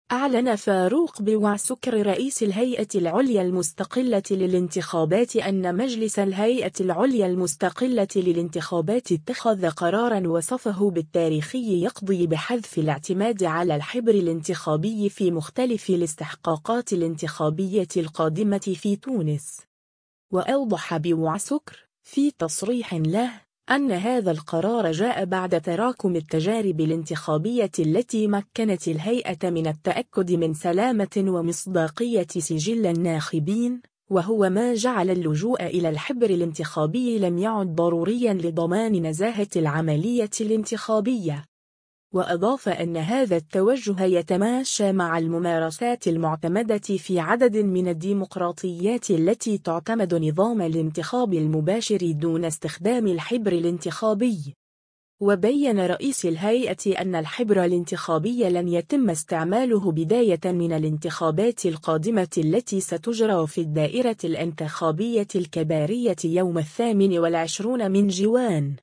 أعلن فاروق بوعسكر رئيس الهيئة العليا المستقلة للانتخابات أن مجلس الهيئة العليا المستقلة للانتخابات اتخذ قرارًا وصفه بالتاريخي يقضي بحذف الاعتماد على الحبر الانتخابي في مختلف الاستحقاقات الانتخابية القادمة في تونس.
و أوضح بوعسكر، في تصريح له، أن هذا القرار جاء بعد تراكم التجارب الانتخابية التي مكّنت الهيئة من التأكد من سلامة ومصداقية سجل الناخبين، وهو ما جعل اللجوء إلى الحبر الانتخابي لم يعد ضروريًا لضمان نزاهة العملية الانتخابية.